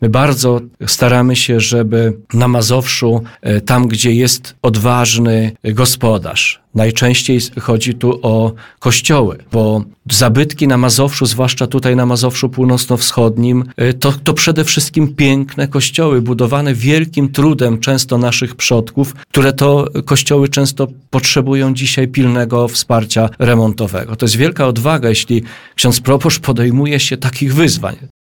Na antenie Radia Nadzieja radny sejmiku województwa Janusz Kotowski, który zasiada w Komisji Kultury i Dziedzictwa Narodowego, przypomniał, że podobne programy w województwie mazowieckim z powodzeniem realizowane są od lat.